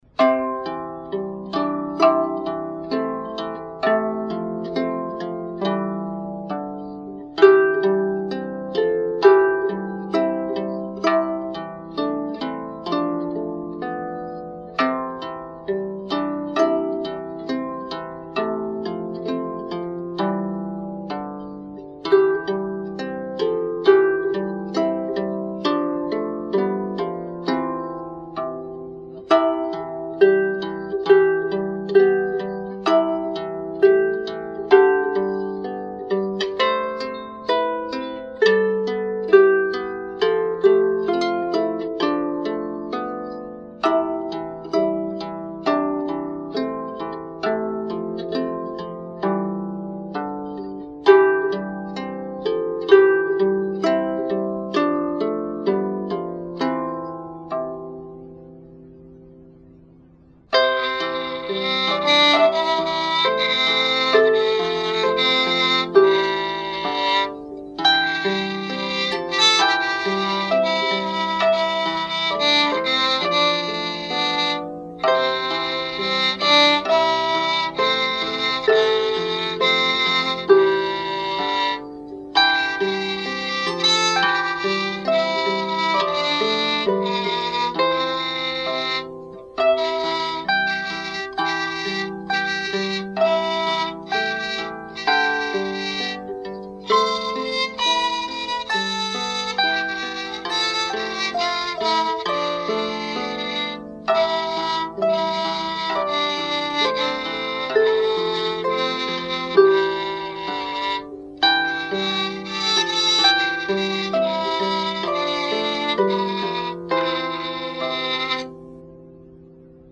Also known as “Bonnie Charlie,” this is a traditional Scottish folk tune, author unknown.
I hope you enjoy this arrangement played on lyre, viola, Oriole (soprano) and Yamaha alto recorder!